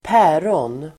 Ladda ner uttalet
Uttal: [²p'ä:rån]